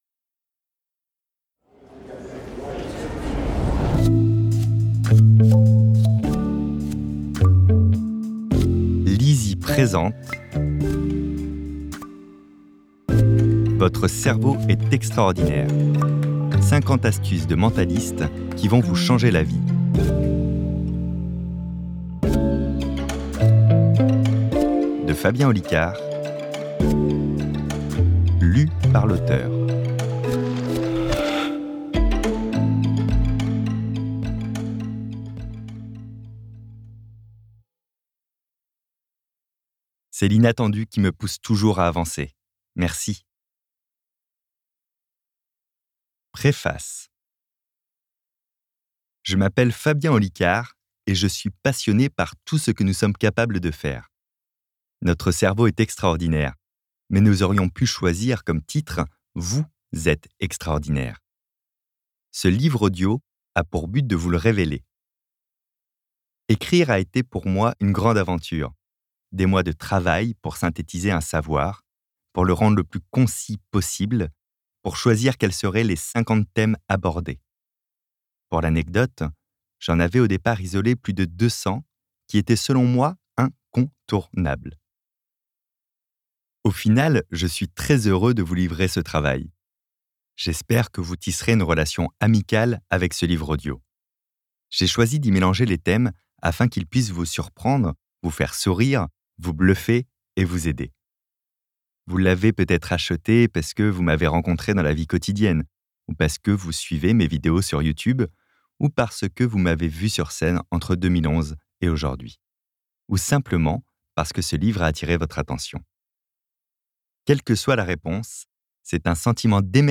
copyparty md/au/audiobook/Fabien Olicard - Votre cerveau est extraordinaire